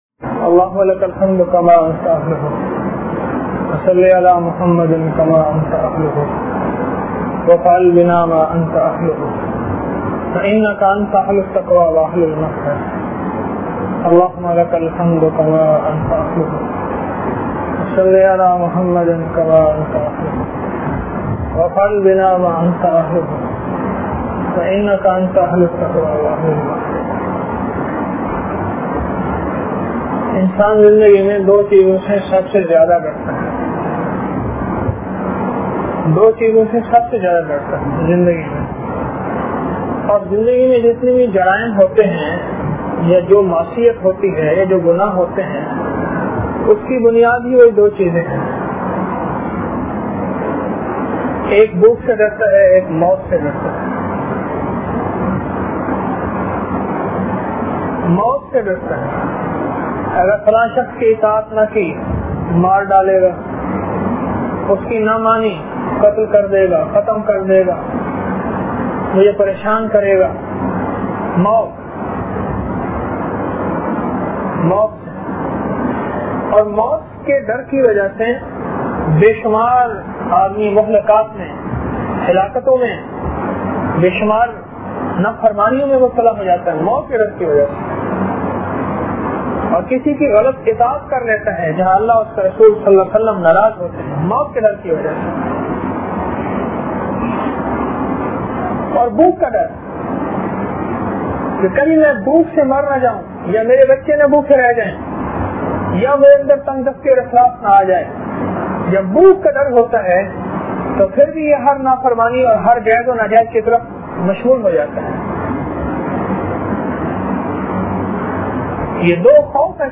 درس روحانیت وامن - 31 اگست 2004